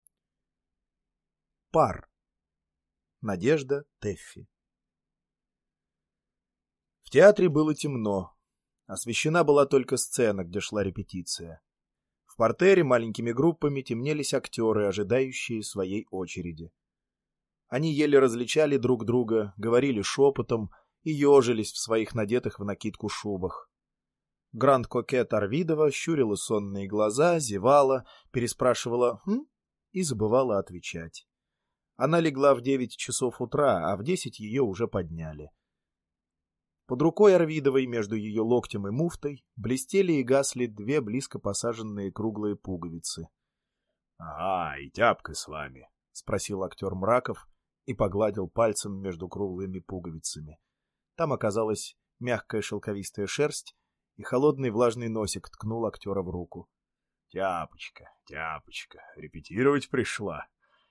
Аудиокнига Пар | Библиотека аудиокниг